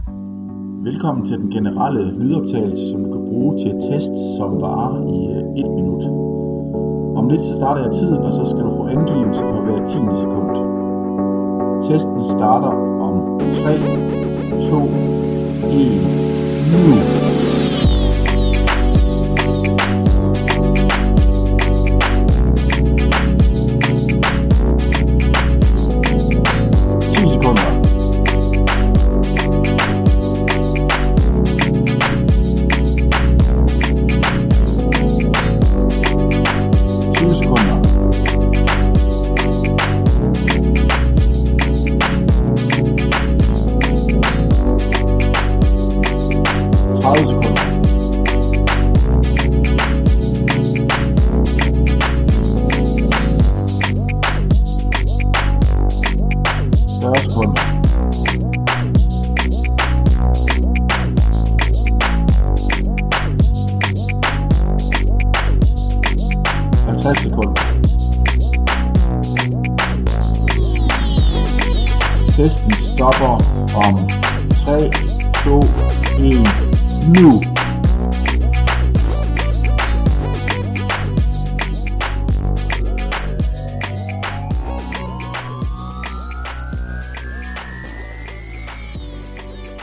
Lydvejledning til styrkeøvlser af 1. minuts varighed.